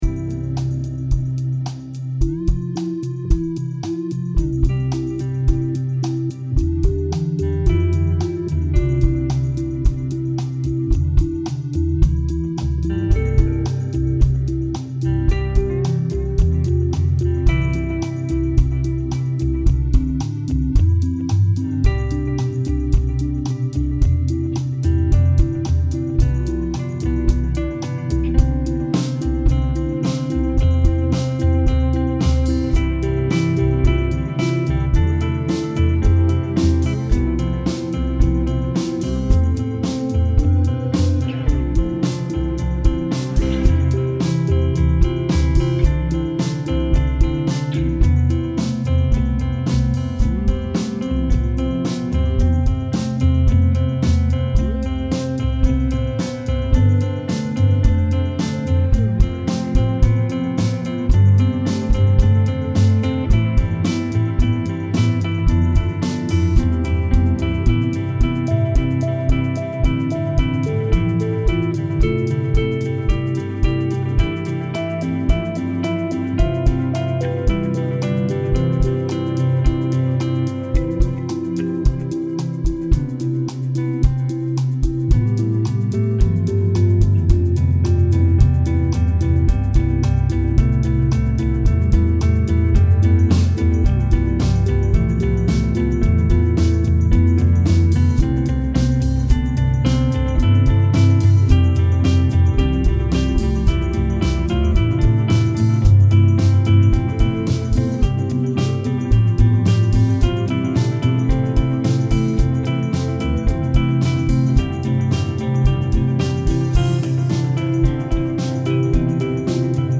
Track inspired by a couple of cool iPhone apps.  Added multiple guitars at varying octaves and acoustic drum kit to round out the demo/experiment…